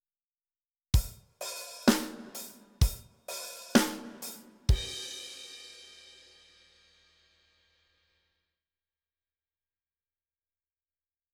171ページ：ドラムパターン4
Drums-Training-4.wav